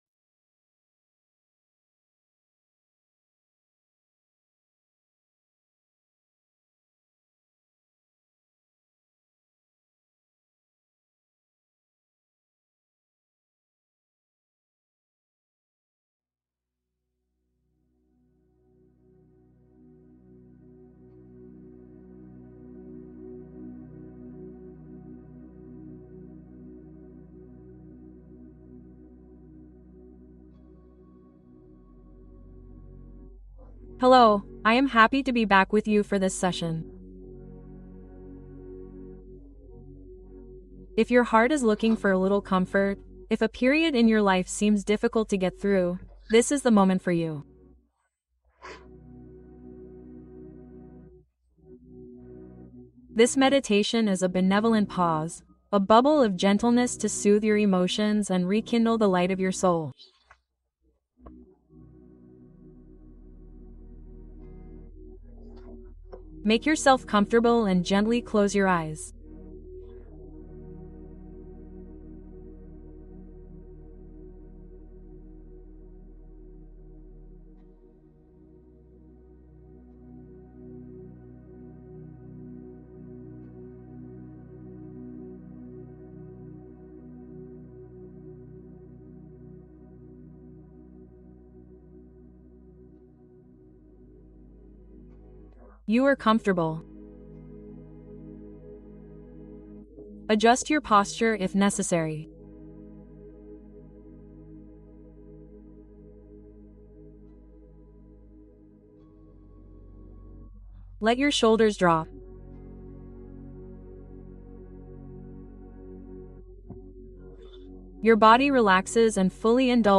Le réconfort profond dont votre âme a désespérément besoin (méditation guidée émotionnelle)